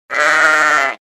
zvuk-barana_007
zvuk-barana_007.mp3